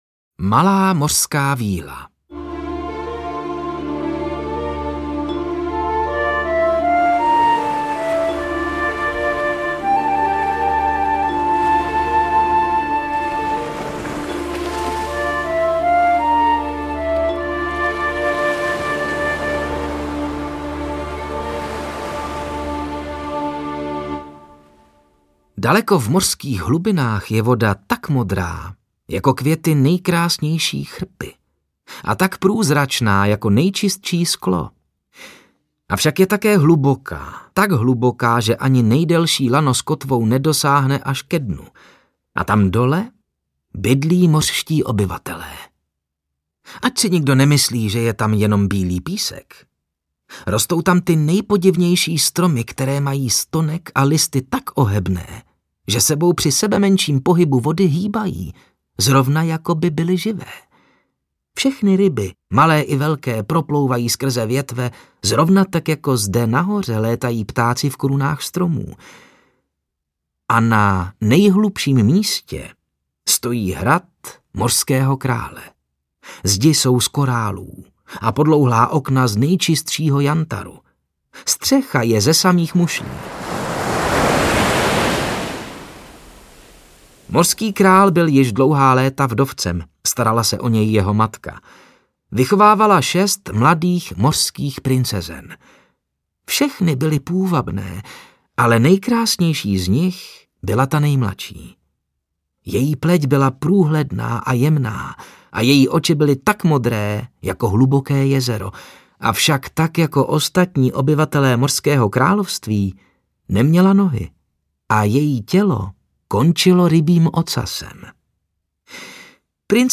Velká sbírka pohádek pro každou rodinu! 23 pohádek - 375 minut České národní pohádky v podání Václava Kopty, Miroslava Táborského, Matouše Rumla, Davida Novotného, Pavla Rímského, Aleše Procházky a dalších oblíbených herců a hereček. Nové nahrávky, tradiční zpracování, excelentní interpreti, hudební doprovod.